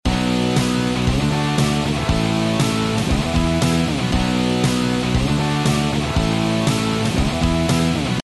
seattle-song-loop-1_gktq2-4u.mp3